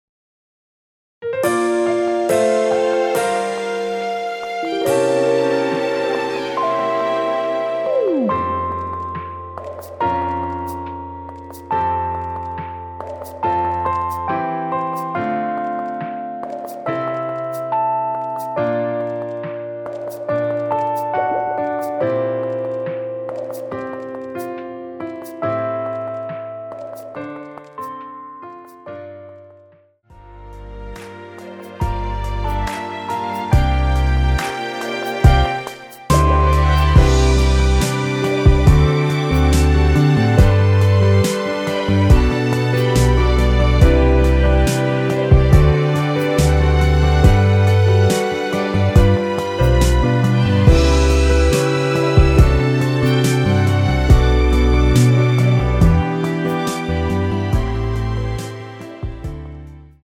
원키에서(+3)올린 MR입니다.
앞부분30초, 뒷부분30초씩 편집해서 올려 드리고 있습니다.